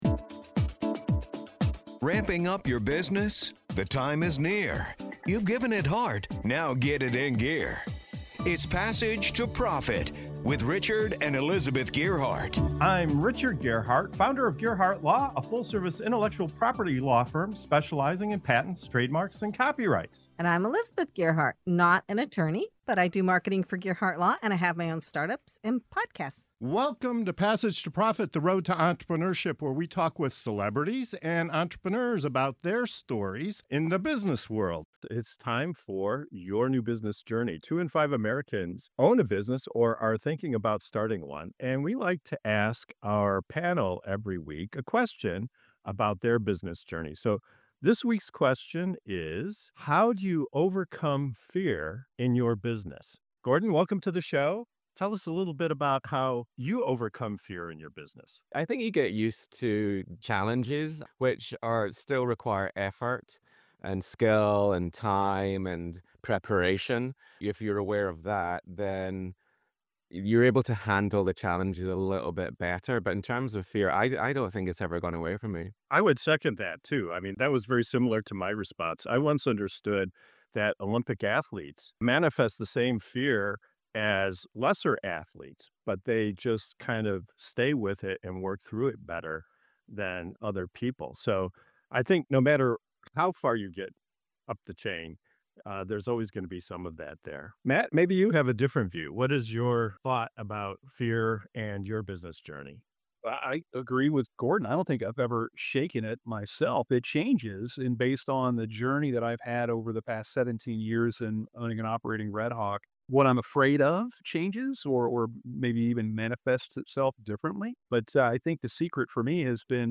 In this segment of "Your New Business Journey" on Passage to Profit Show, our panel of entrepreneurs shares their personal experiences with fear—how it evolves over time, how they manage it, and how they turn it into a driving force for growth. From embracing "positive stress" to tackling acquisitions and stepping into leadership roles, they reveal the mindset shifts and strategies that keep them moving forward.